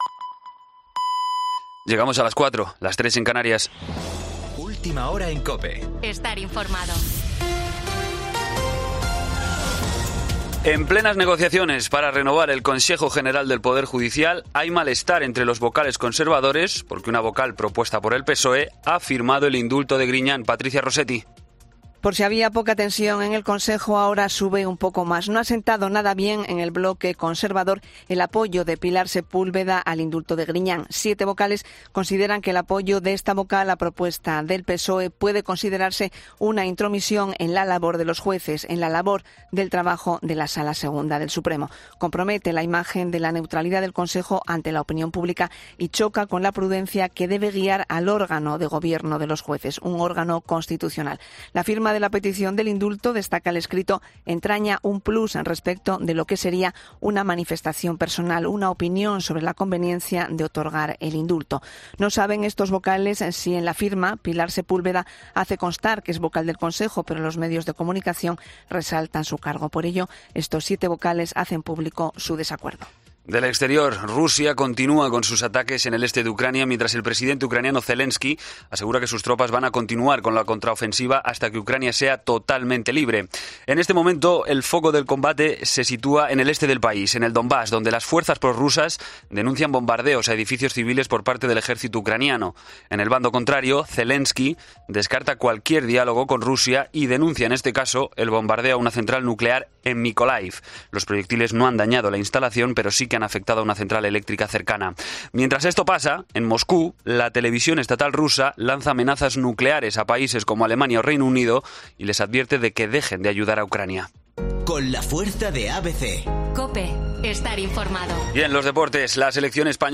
Boletín de noticias COPE del 20 de septiembre a las 04:00 hora